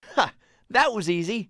Voice Actor Information